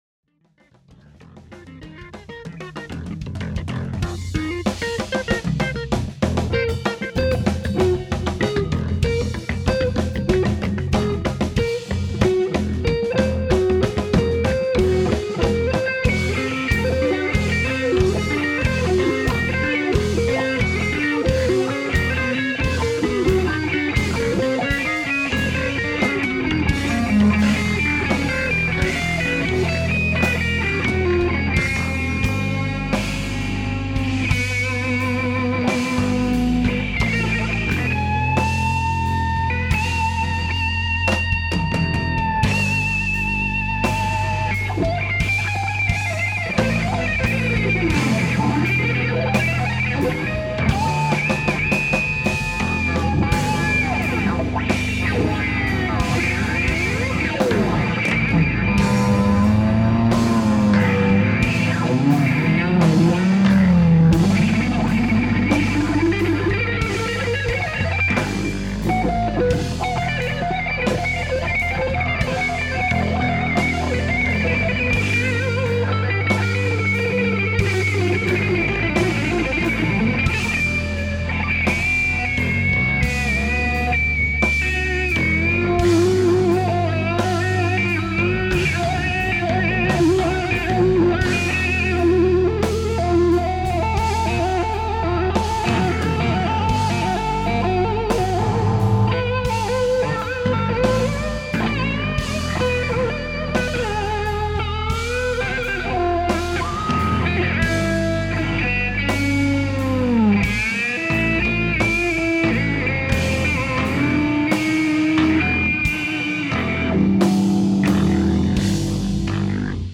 an instrumental trio
Warr guitar
drums